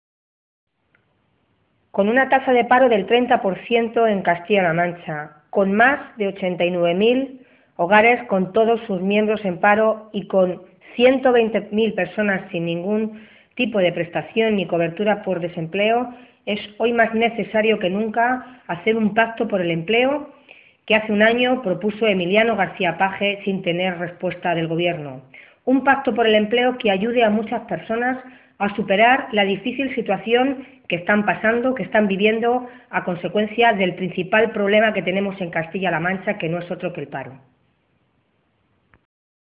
La portavoz de Empleo del PSOE en las Cortes regionales, Milagros Tolón, ha pedido hoy al Gobierno de Cospedal que “rectifique, escuche no solo a la oposición, sino a todos los agentes económicos y sociales y se ponga manos a la obra para abordar el principal problema que tenemos, que es el paro, a través de un gran pacto regional por el empleo”.
Cortes de audio de la rueda de prensa